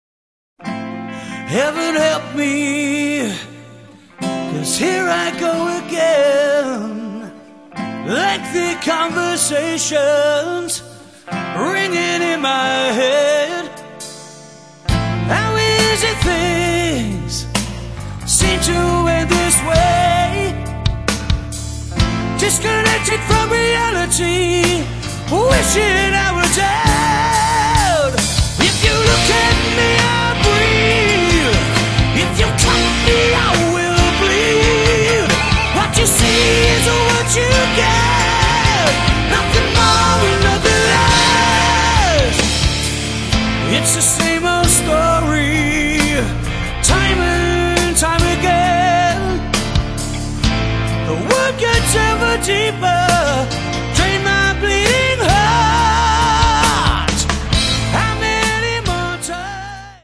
Metal
вокал
гитара
бас, бэк-вокал
барабаны, клавиши, бэк-вокал